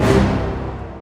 Sizzle Hit 1.wav